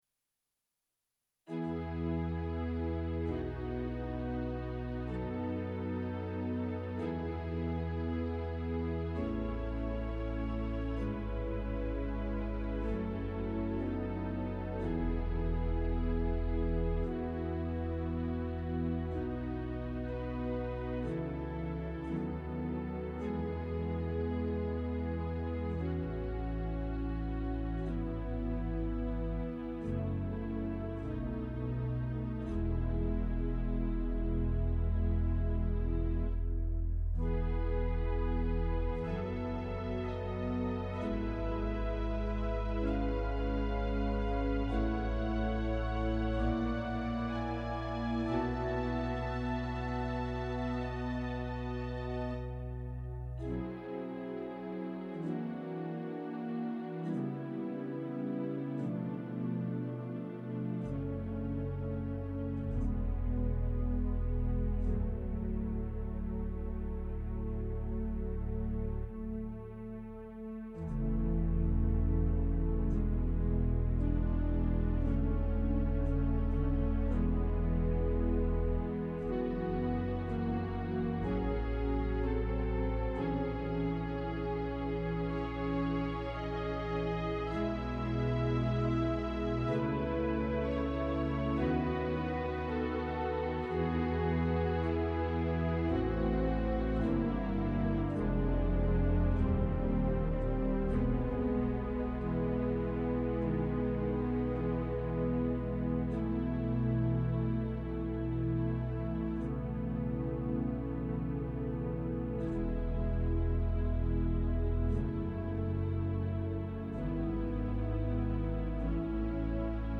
Organ Meditations Audio Gallery
Peaceful works for quiet reflection